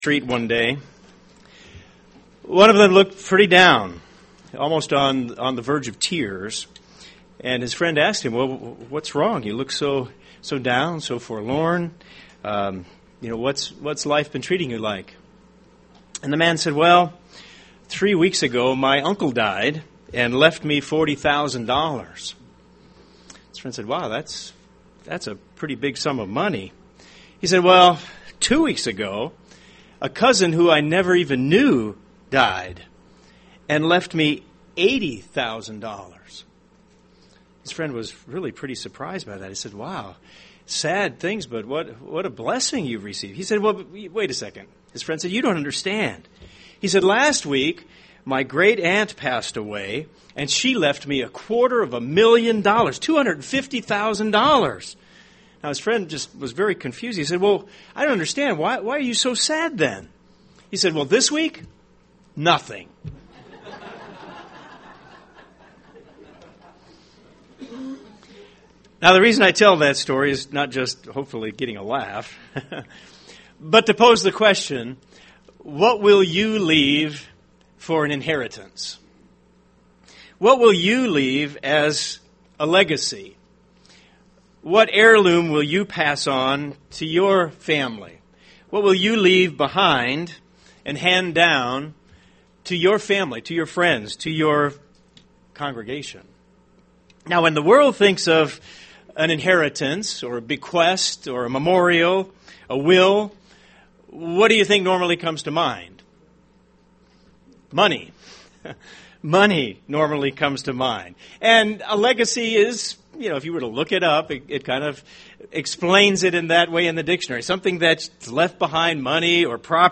UCG Sermon Transcript This transcript was generated by AI and may contain errors.